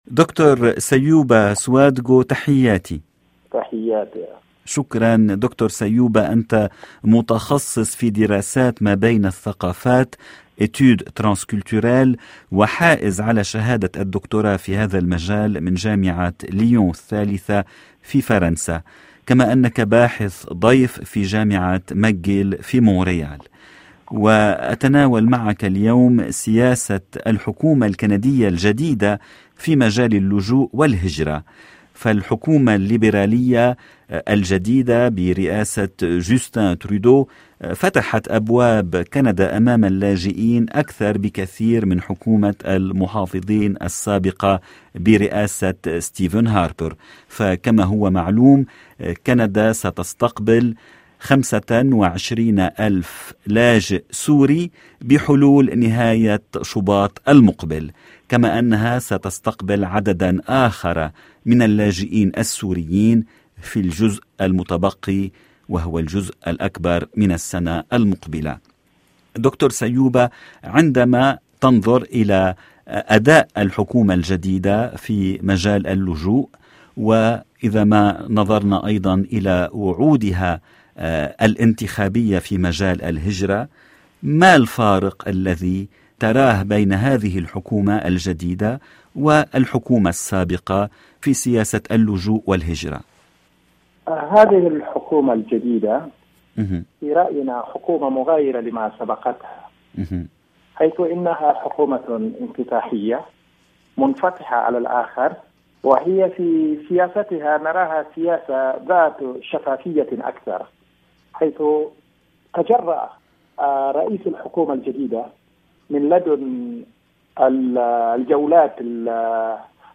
تناول التغيير في مجاليْ اللجوء والهجرة مع الحكومة الجديدة في حديث مع الباحث الضيف